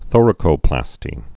(thôrə-kō-plăstē)